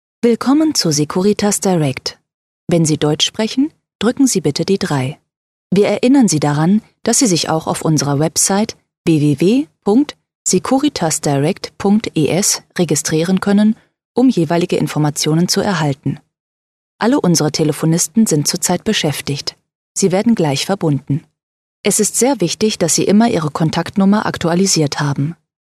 Native speaker Female 30-50 lat
Strong mid-range and vital female voice.
Nagranie lektorskie